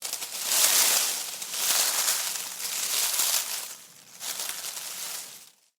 Kicking Leaf Piles
Nature
Kicking Leaf Piles is a free nature sound effect available for download in MP3 format.
yt_8r4kAgILfUI_kicking_leaf_piles.mp3